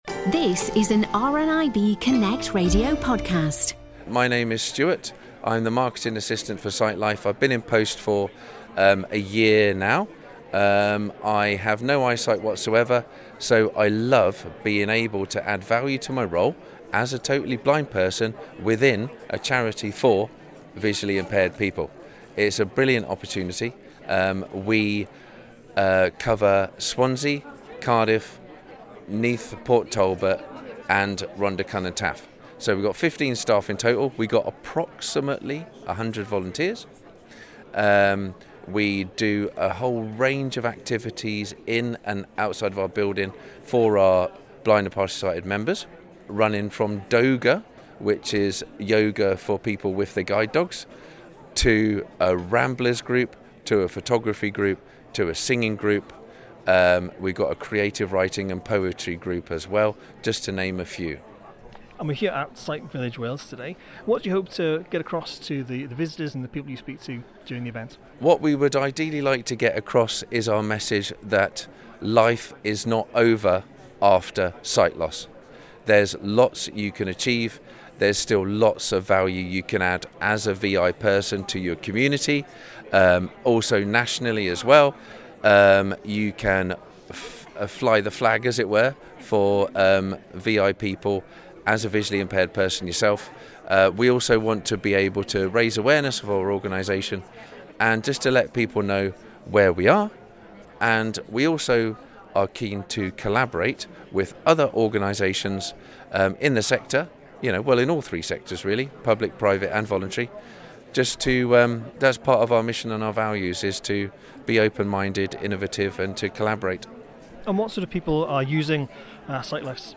At Sight Village Wales 2025